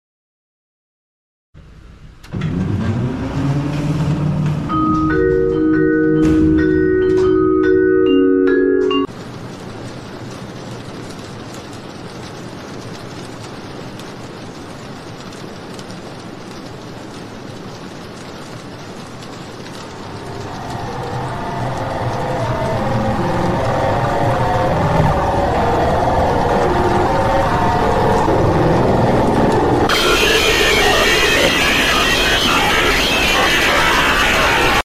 ⚠THIS CONTAINS LOUD NOISES⚠ENTERTAINMENT PURPOSES⚠ sound effects free download